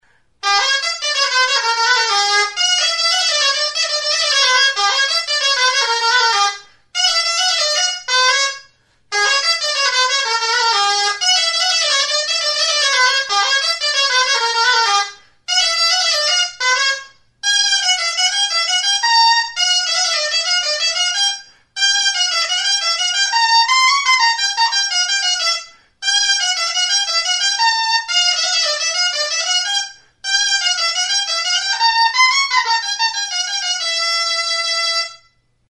Aerophones -> Reeds -> Double (oboe)
Recorded with this music instrument.